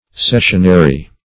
Search Result for " cessionary" : The Collaborative International Dictionary of English v.0.48: Cessionary \Ces"sion*a*ry\, a. [LL. cessionarius, from cessionare to cede, fr. L. cessio: cf. F. cessionnaire.